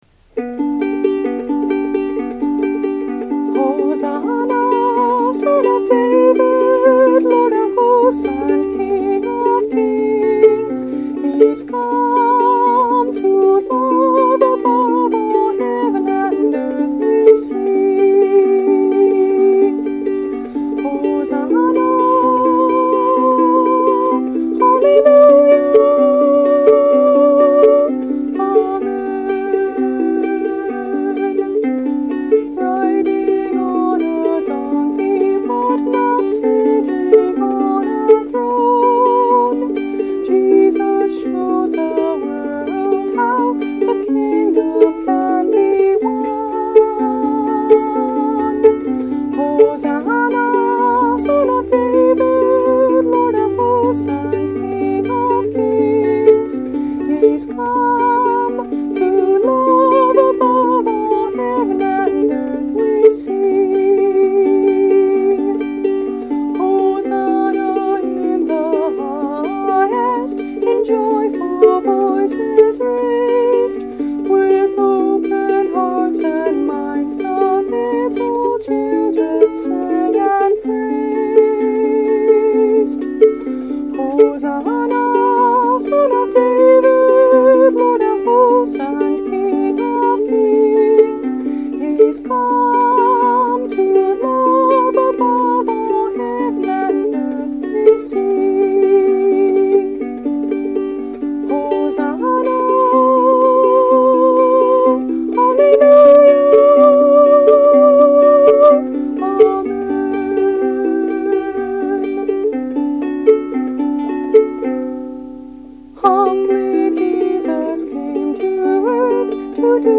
Note: My usual recording device is on the fritz; I apologize for this poorer quality.
Instrument: Nell – Long Neck Soprano Ukulele
(Reentrant Bb tuning)